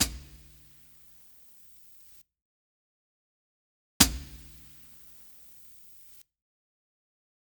Всем привет! Ребяты, а скажите мне те, кто использует эту библиотеку, слышите ли вы "послезвучие" на хетах (особенно на педали, тот семпл, который находится на G#), этакое хррррр...... ? Словно компрессор пытается "вытянуть" эти шумы.
Вложения Hat Foot.wav Hat Foot.wav 1,9 MB · Просмотры: 223